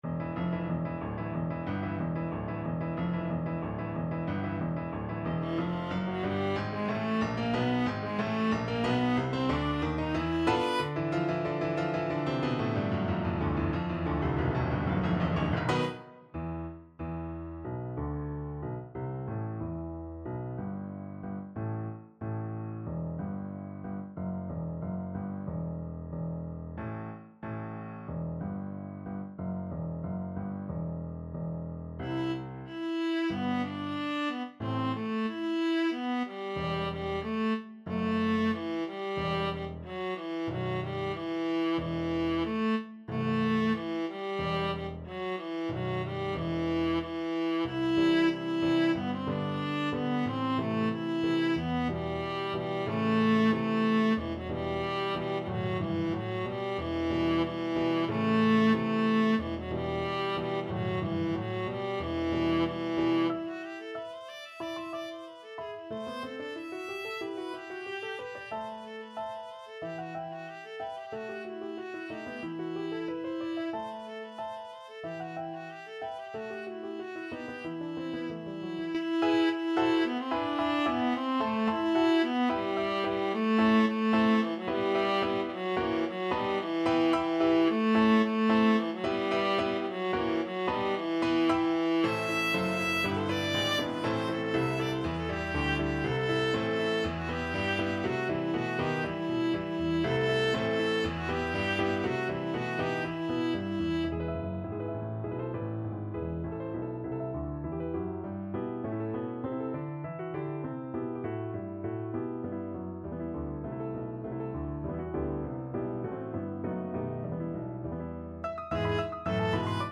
Viola
2/4 (View more 2/4 Music)
E minor (Sounding Pitch) (View more E minor Music for Viola )
Allegro =92 (View more music marked Allegro)
Classical (View more Classical Viola Music)